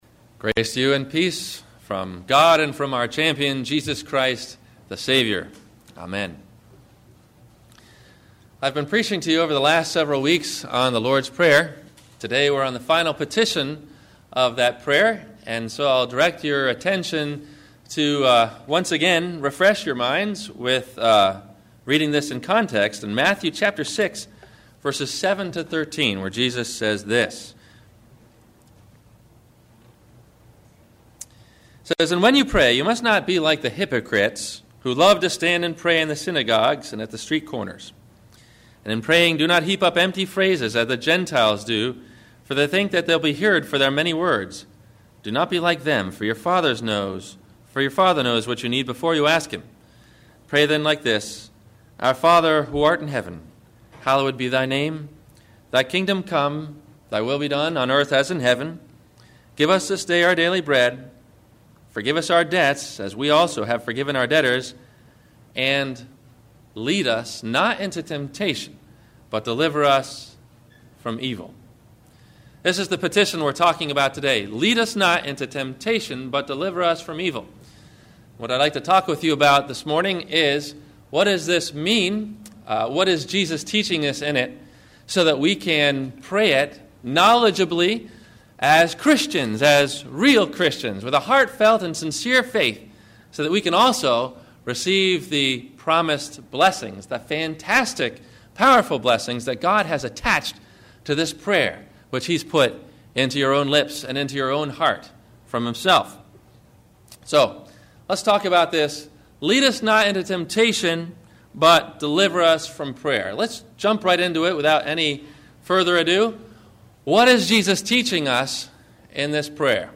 How to Slay a Dragon – Lead Us Not into Temptation but Deliver Us from Evil - Sermon - July 13 2008 - Christ Lutheran Cape Canaveral